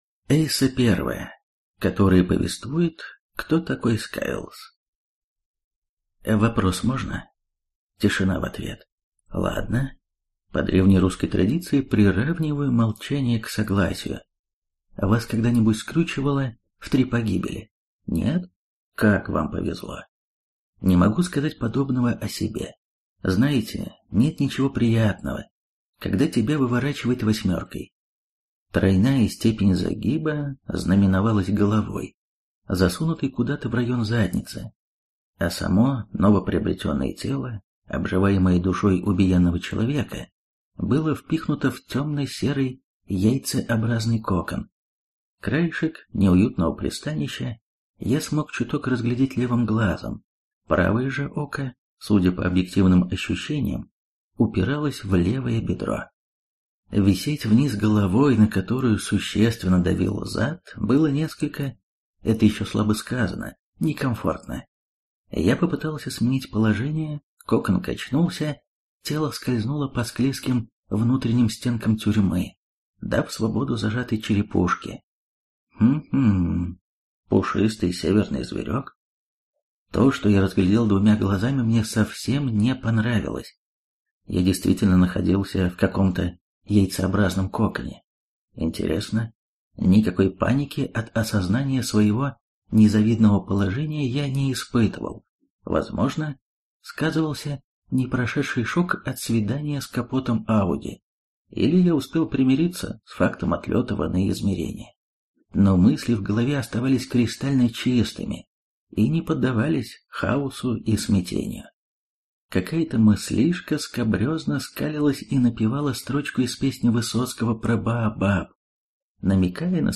Аудиокнига Питомец | Библиотека аудиокниг